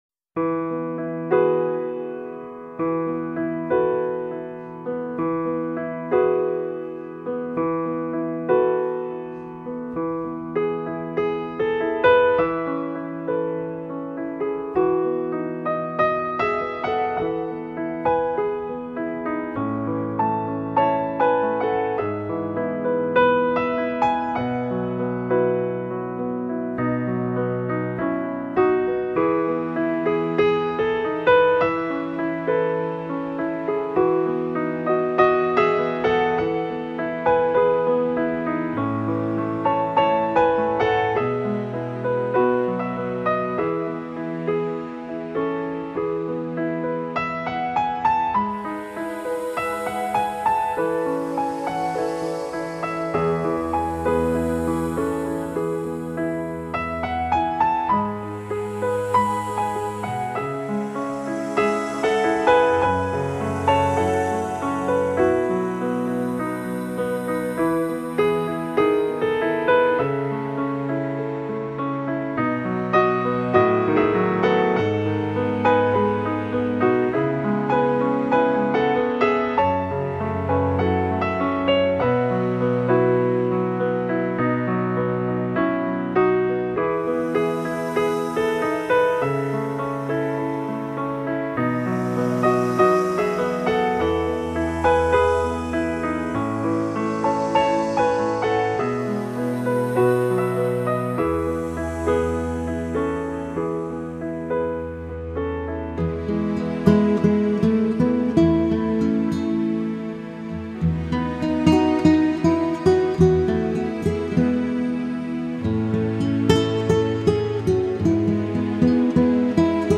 一张从心底触动灵魂感官的钢琴音乐